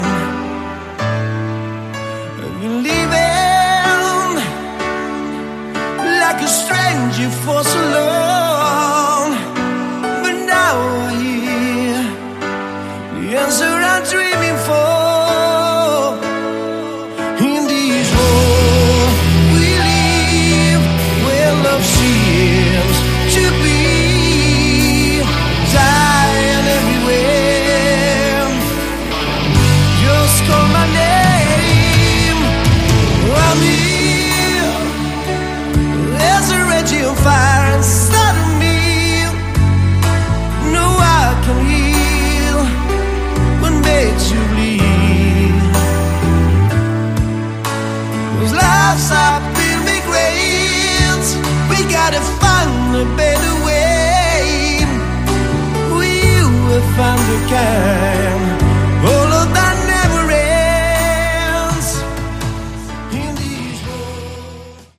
Category: Melodic Rock
Vocals
Guitar
Keyboards
Drums
Bass